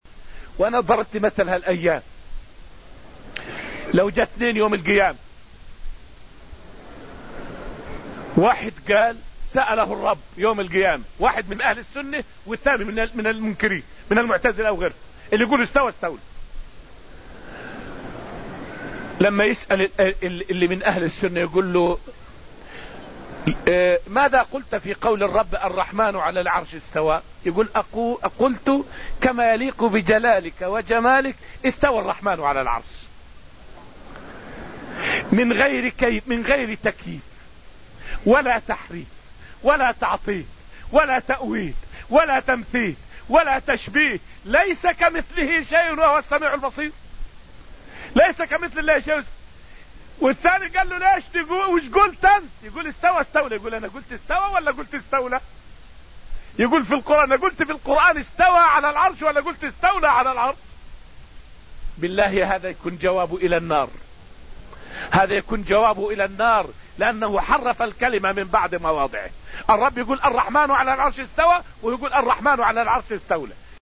فائدة من الدرس الثالث من دروس تفسير سورة الحديد والتي ألقيت في المسجد النبوي الشريف حول قوة حجة أهل السنة مقابل أهل الأهواء.